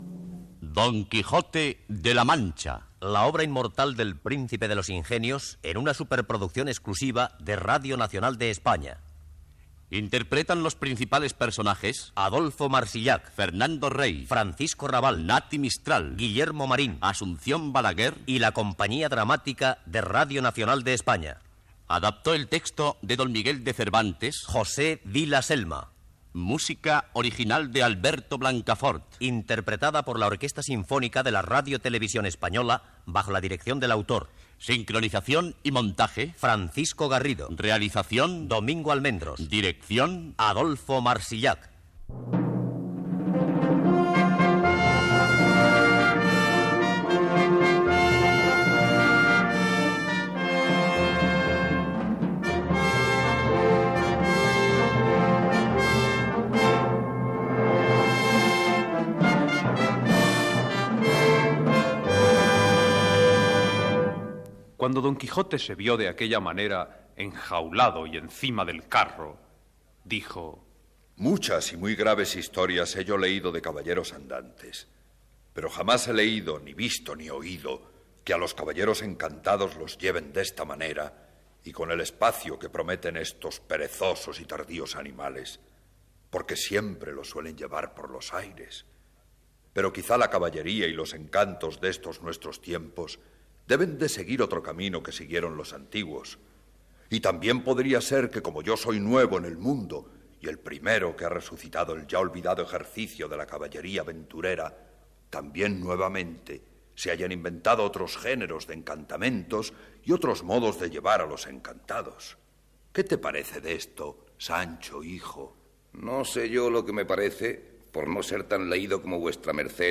Careta de l'adaptació radiofònica de "El Quijote" de Miguel de Cervantes i fragment de l'obra, amb Fernando Rey (El Quijote) i Paco Rabal (Sancho Panza).
Ficció